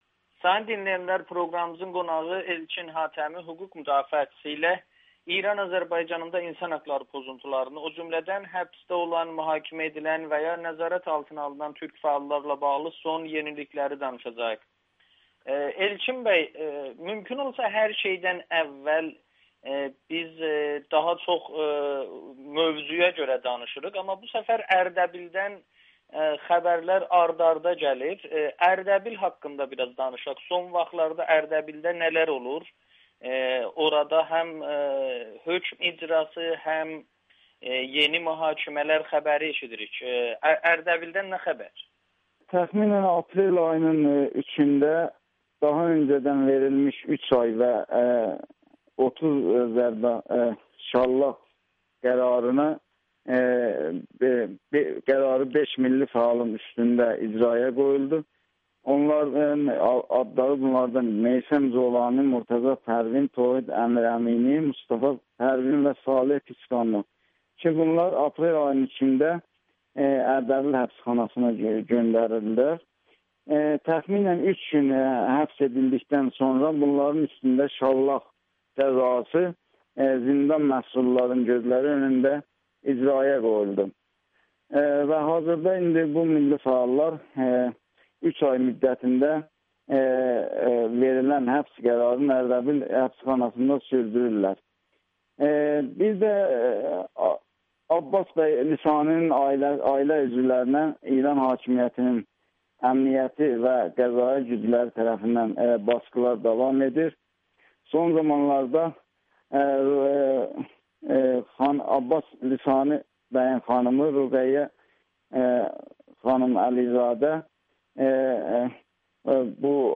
Ərdəbildə basqıların artması fəalları susdurmaq üçündür [Audio-Müsahibə]